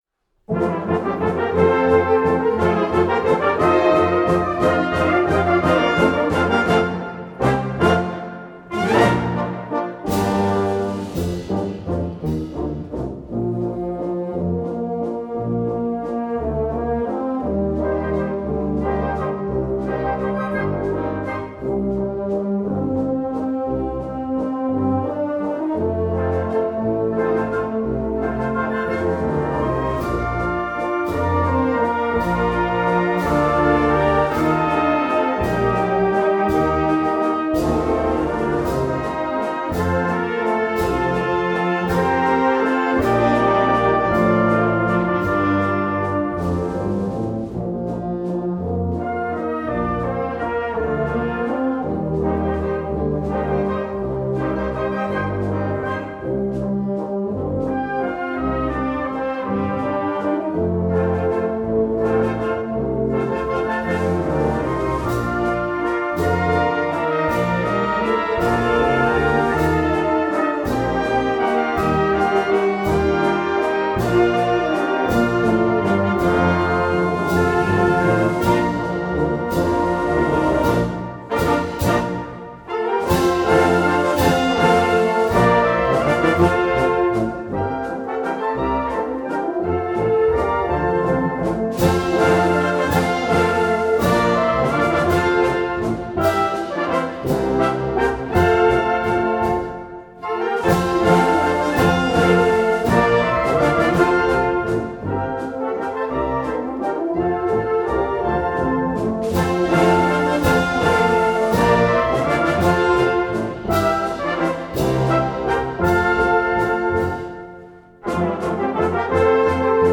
Gattung: Walzer für Blasorchester
Besetzung: Blasorchester
Walzer für Blasorchester